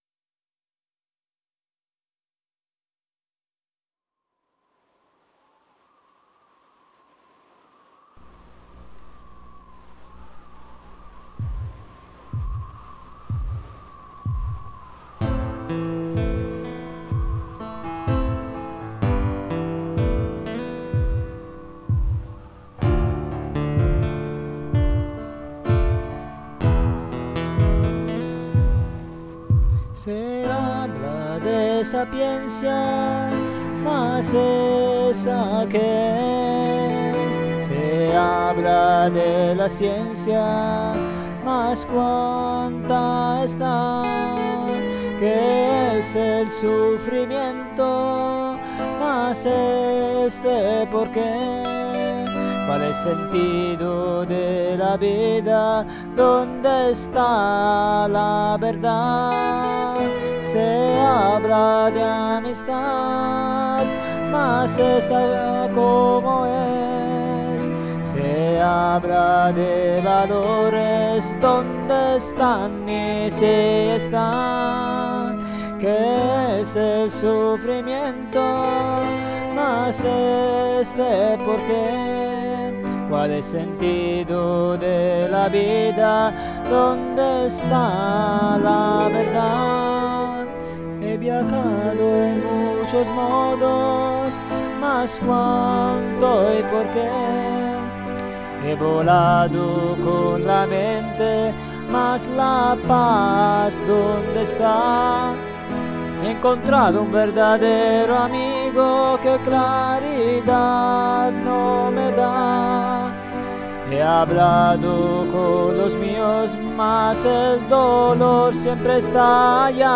notas de guitarra